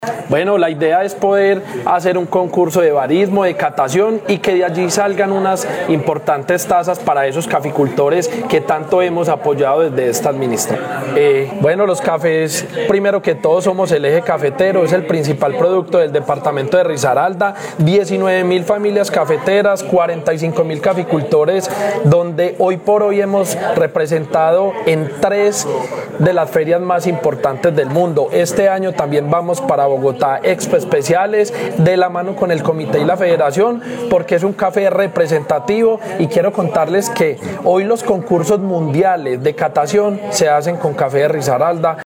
Secretario-de-Desarrollo-Agropecuario-Juan-Carlos-Toro-Cafe-Especial.mp3